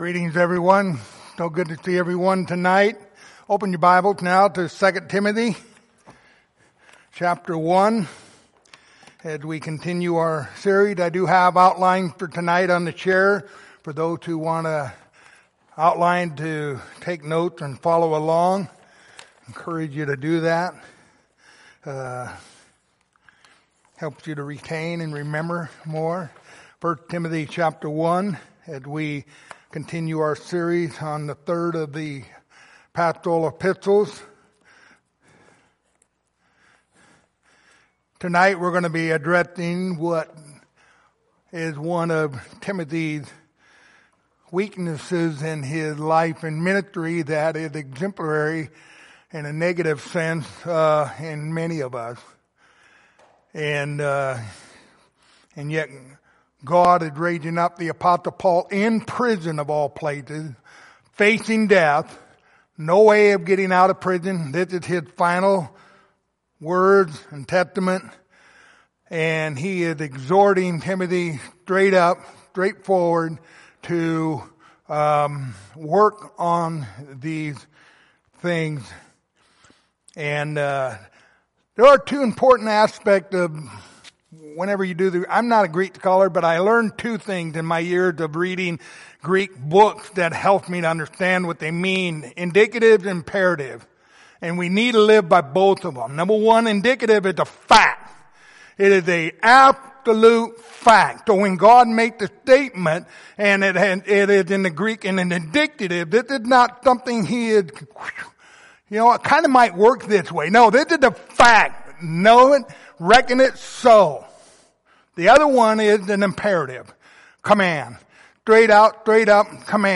Passage: 2 Timothy 1:6-7 Service Type: Sunday Evening